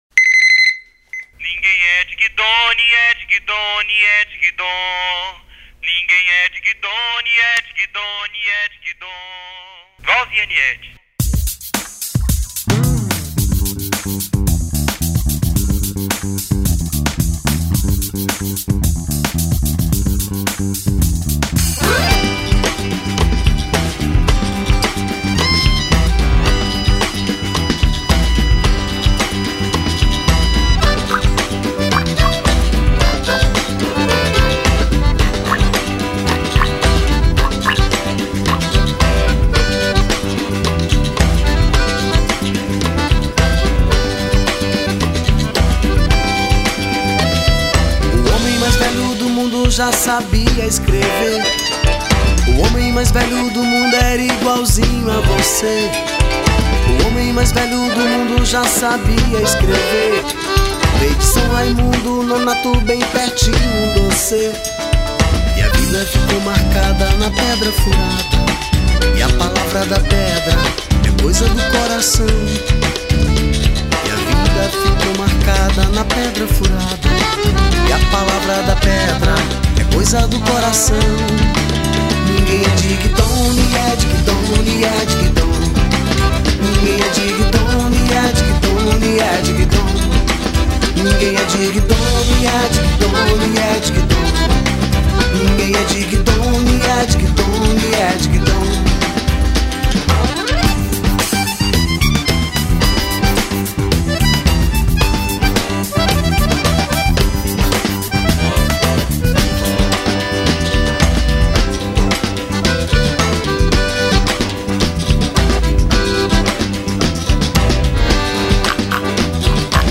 2964   04:57:00   Faixa: 7    Rock Nacional